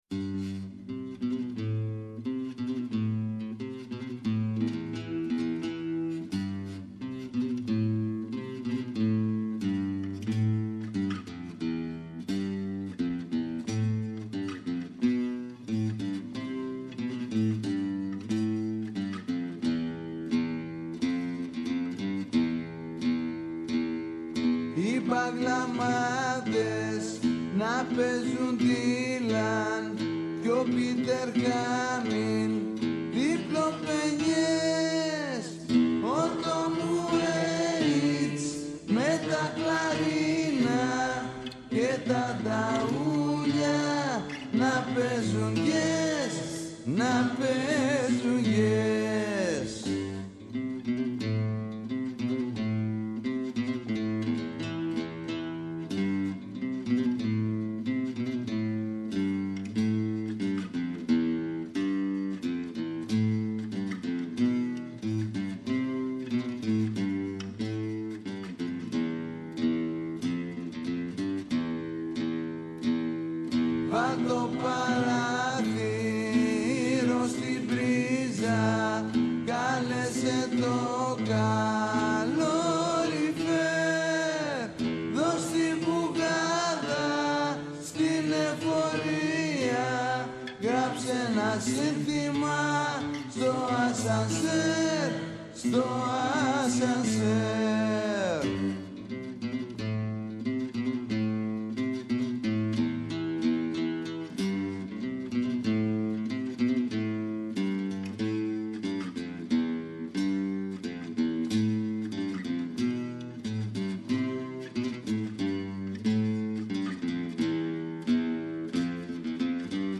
Καλεσμένοι τηλεφωνικά στην σημερινή εκπομπή: